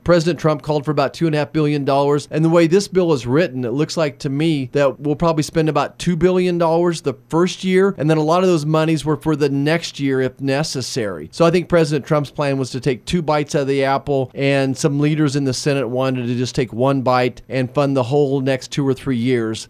Marshall, in an interview with KMAN Friday said money from the bill will also help support local governments respond to the disease.